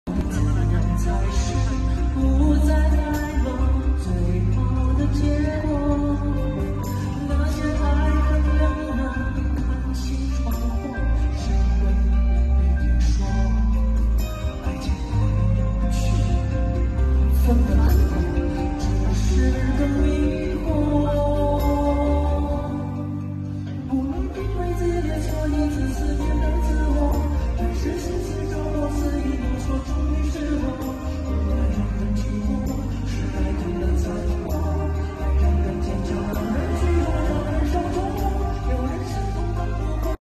Laser Light Sound Effects Free Download